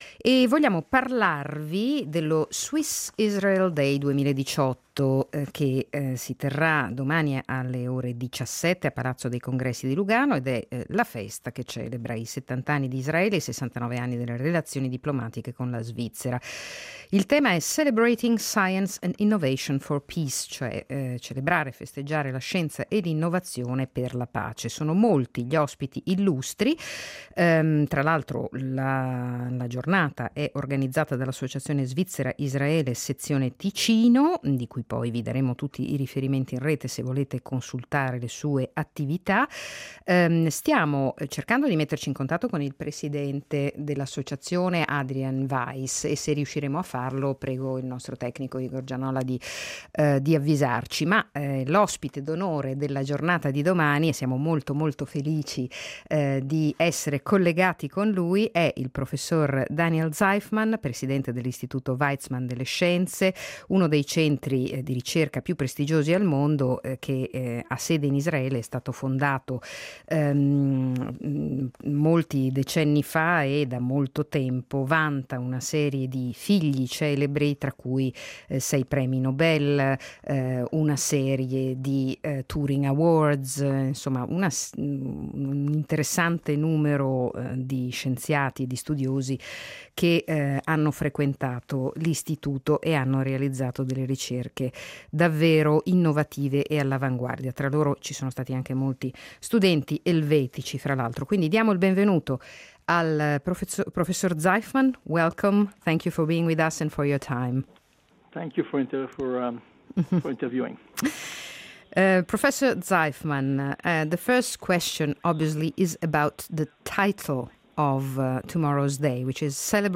Intervista con Daniel Zajfman, Presidente delle celebre Weizmann Institute di Rehovot (Israele), in occasione delle celebrazioni dello Swiss Israel Day 2018 a Palazzo dei Congressi di Lugano.